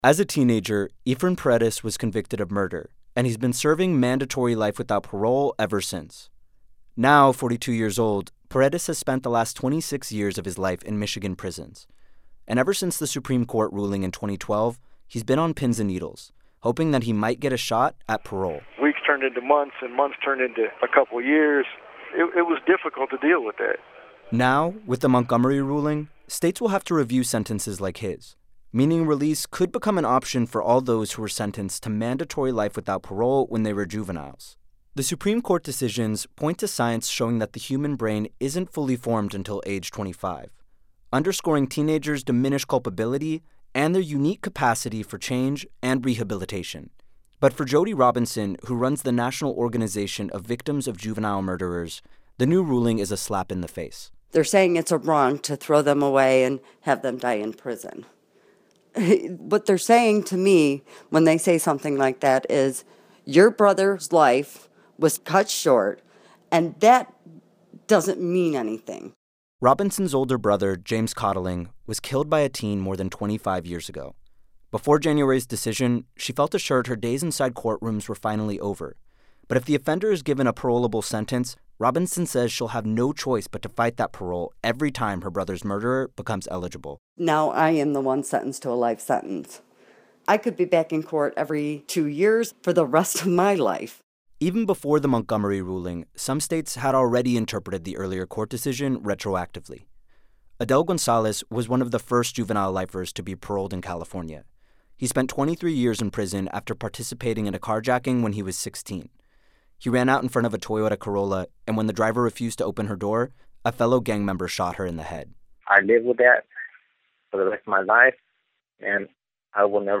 Originally aired on NPR’s All Things Considered on February 15, 2016